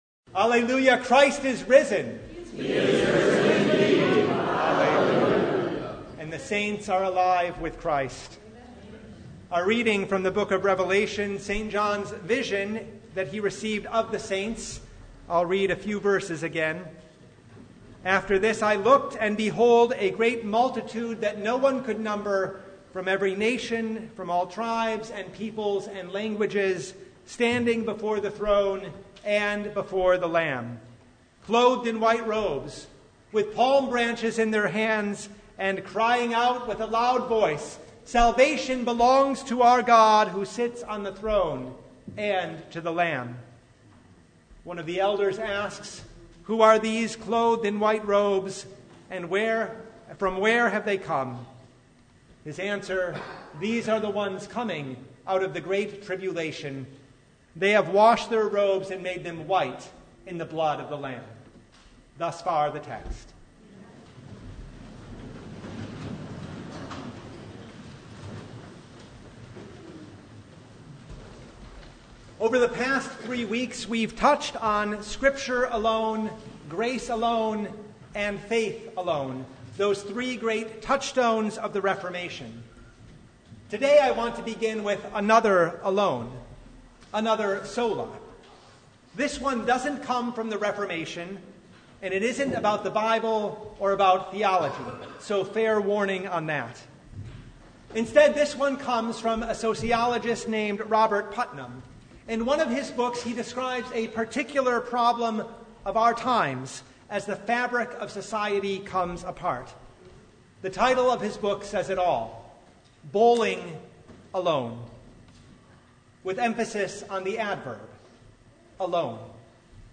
Revelation 7:9-17 Service Type: The Feast of All Saints' Day With St. John on the island of Patmos